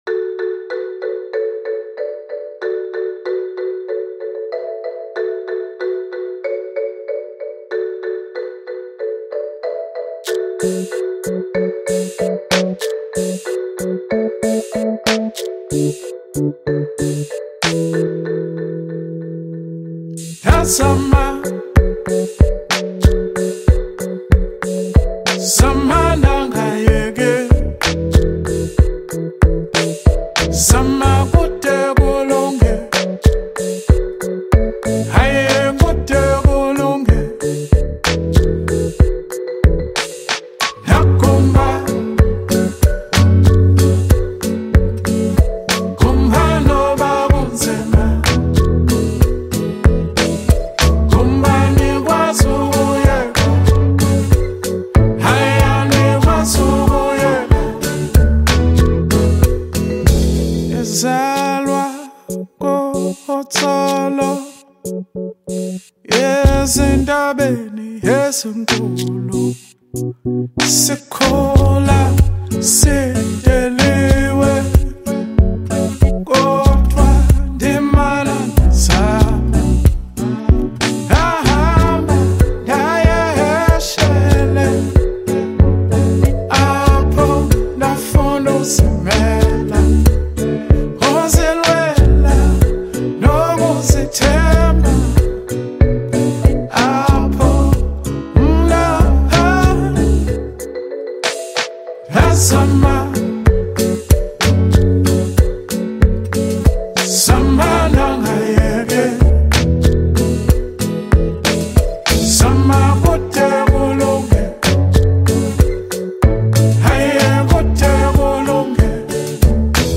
is a groovy anthem